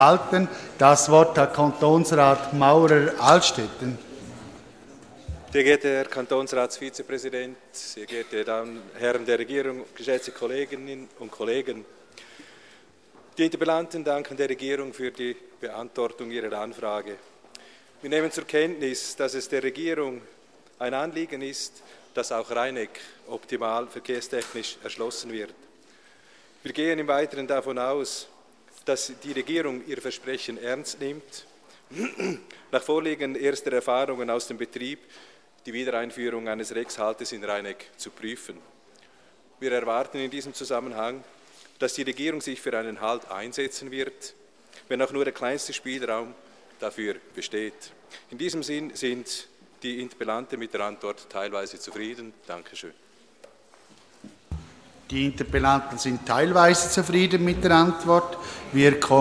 16.9.2013Wortmeldung
Session des Kantonsrates vom 16. bis 18. September 2013